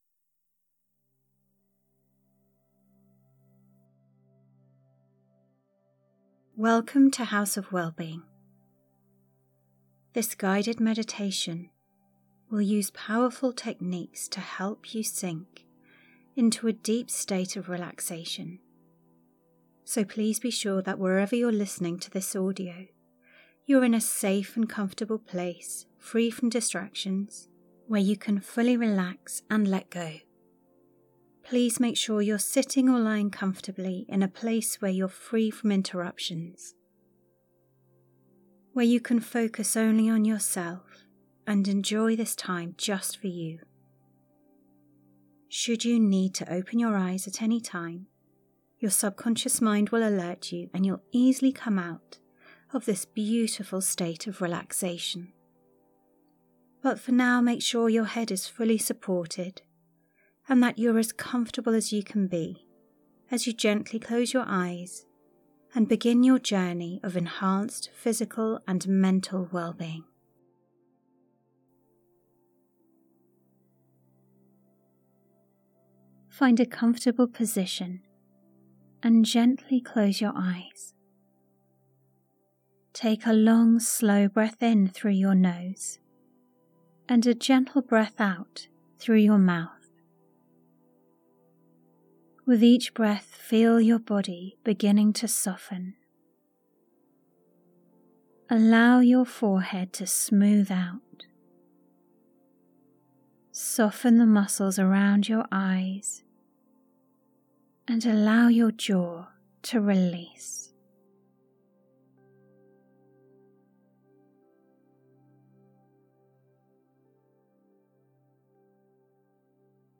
This Believing In Yourself Acupressure meditation is an empowering audio that combines breath, affirmations, and acupressure points on the chest, shoulders, collarbone, and hands. The practice helps release self-doubt, ease heavy feelings, and build confidence, leaving you with clarity, courage, and the belief that you are enough, exactly as you are.